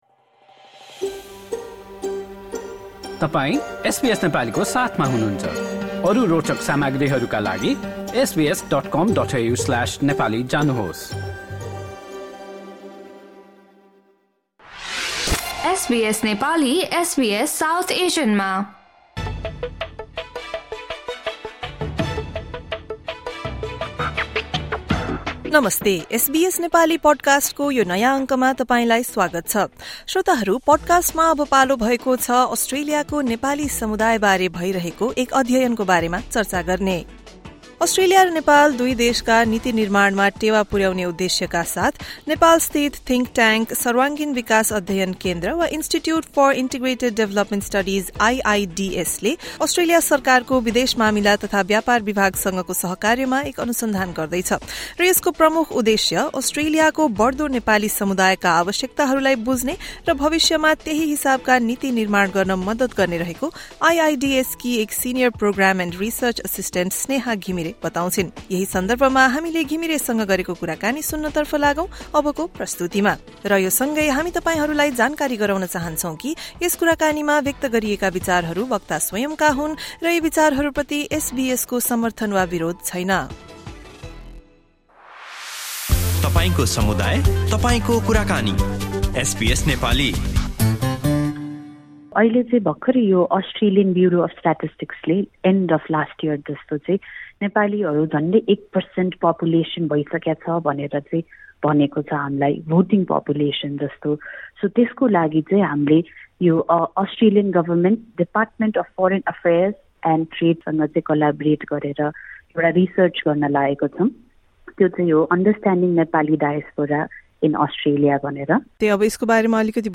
एसबीएस नेपालीसँगको कुराकानीमा